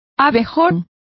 Complete with pronunciation of the translation of bumblebees.